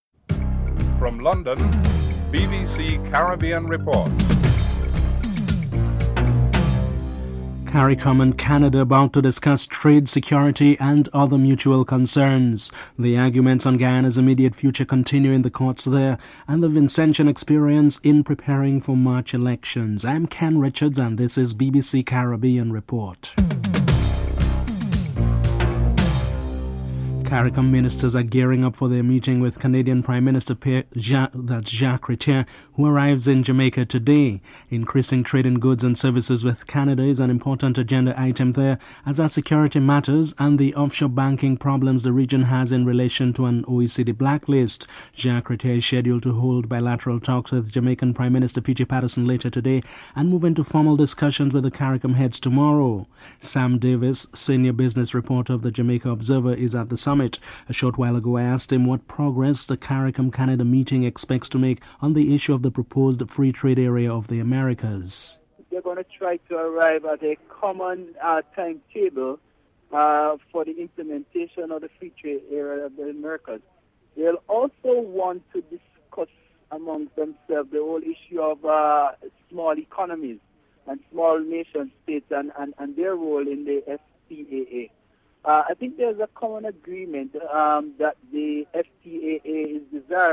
1. Headlines (00:00-00:25)